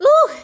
daisy_panting.ogg